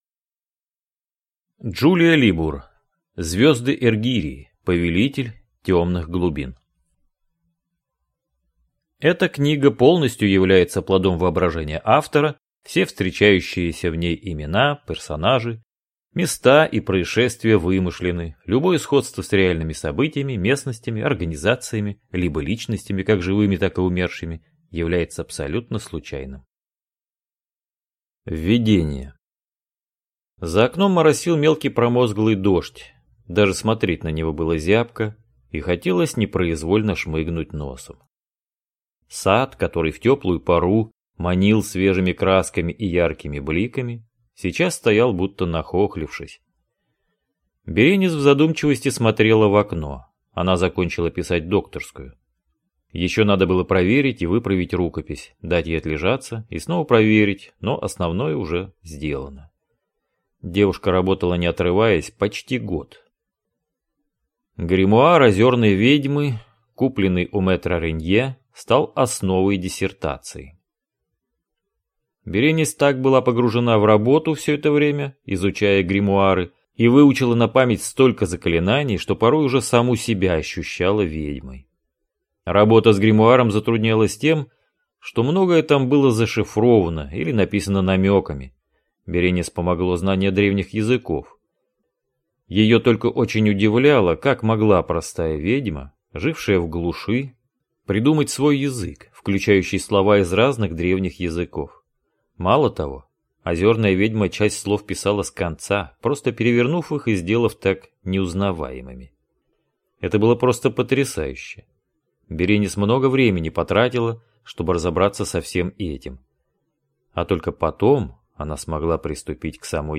Аудиокнига Звёзды Эргирии. Повелитель Тёмных Глубин | Библиотека аудиокниг